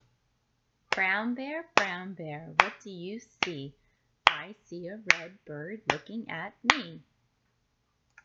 But anyway, this story naturally falls into a rhythm that you and your child can play a steady beat to throughout.